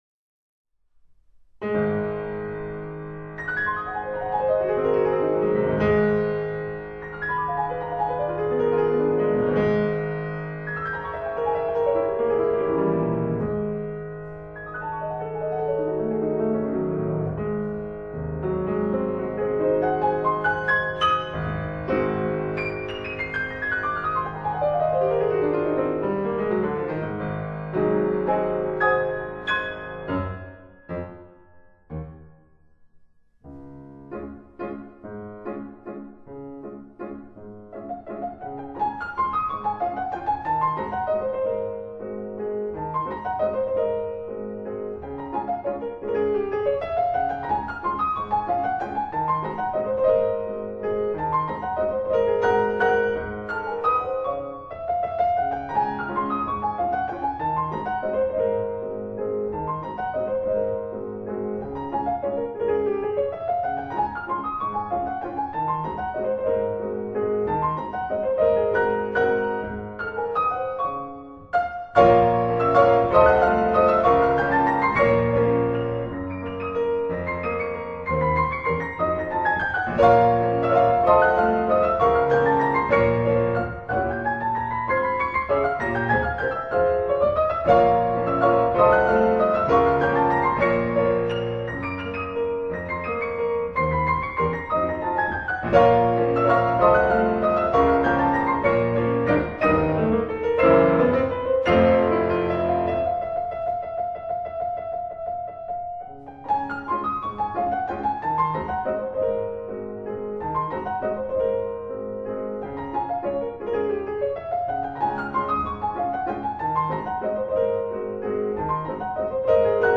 pour piano à 4 mains